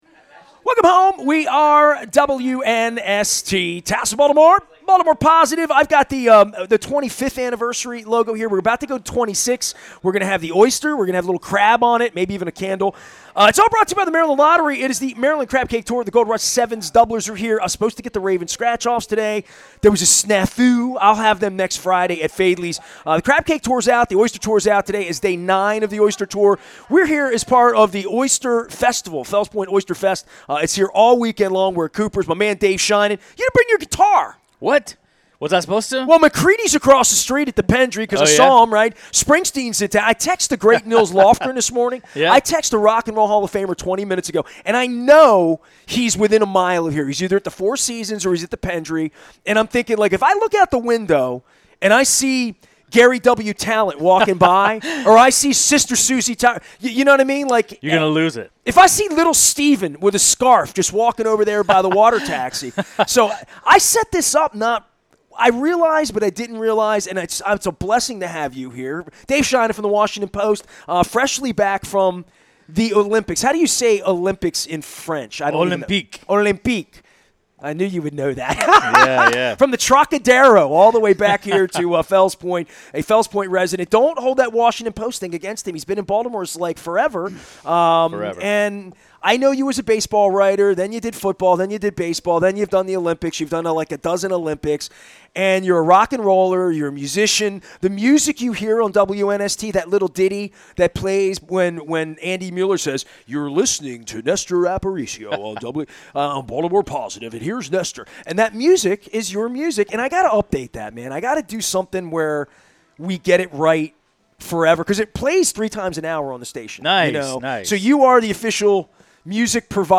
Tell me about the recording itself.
at Kooper's Pub on The Maryland Crab Cake Tour